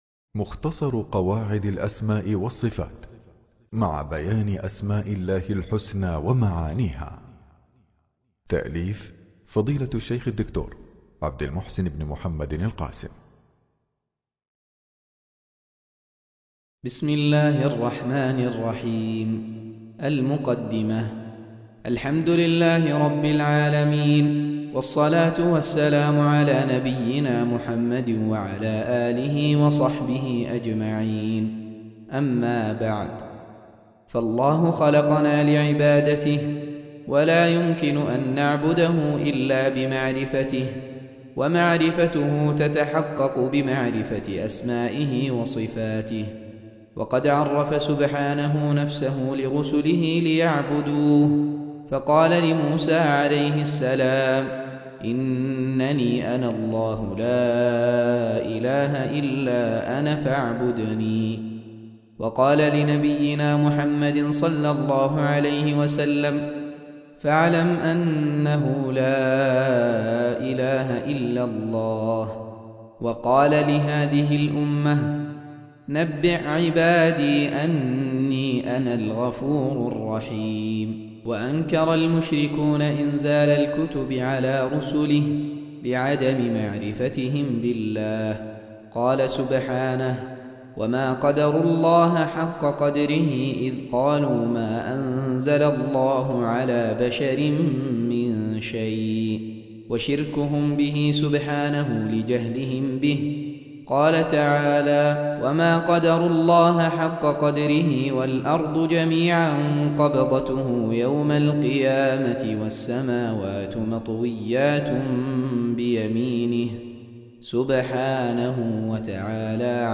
مختصر قواعد الأسماء والصفات قراءة